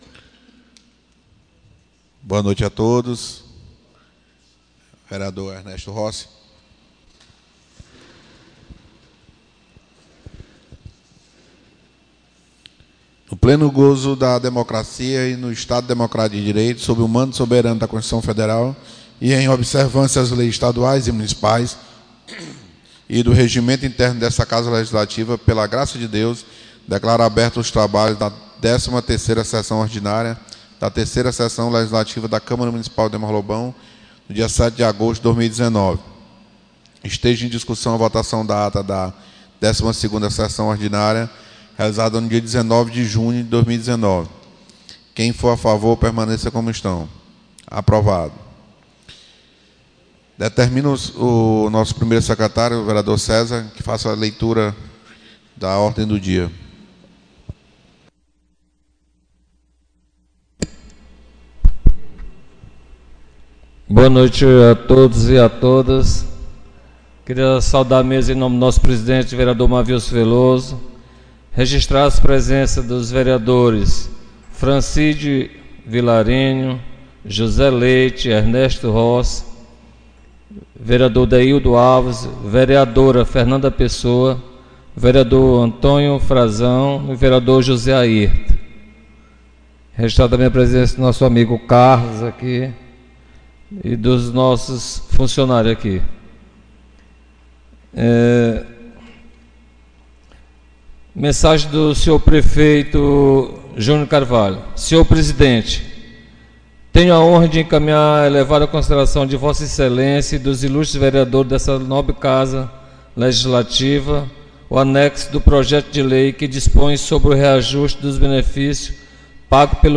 13ª Sessão Ordinária 07 de Agosto